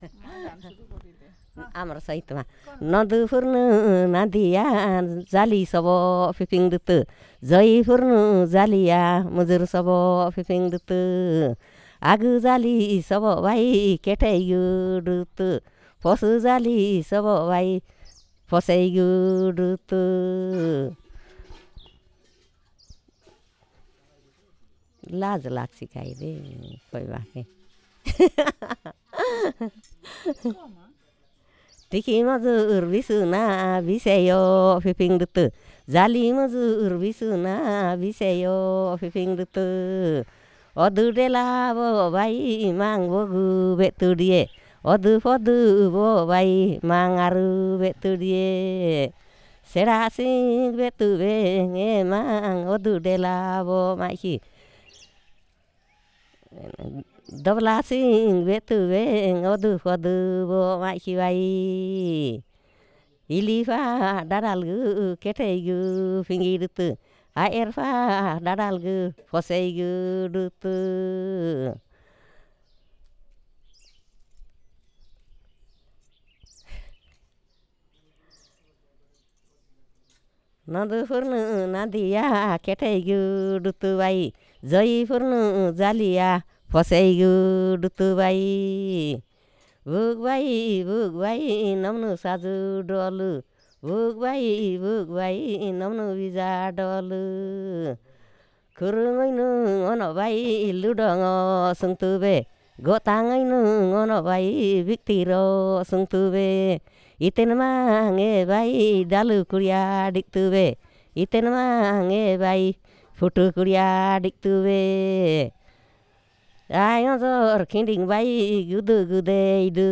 Perfomance of folk song